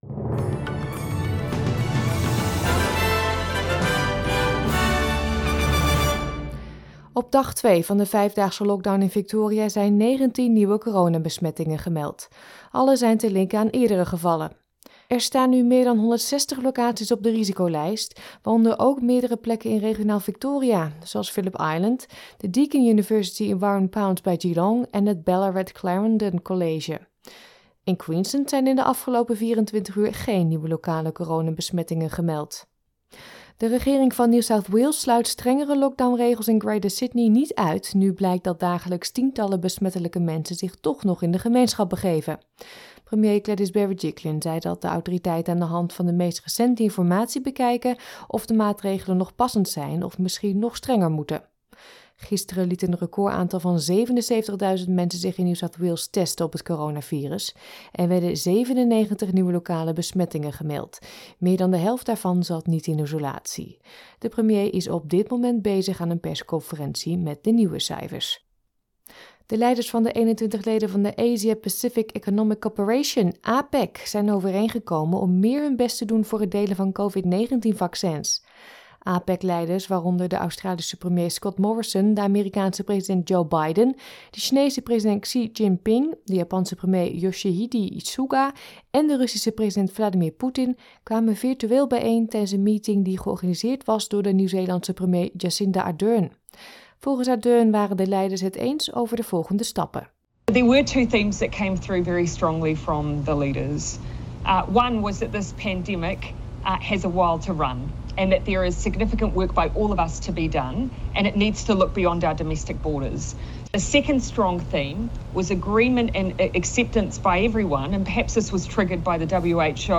Nederlands/Australisch SBS Dutch nieuwsbulletin van zaterdag 17 juli 2021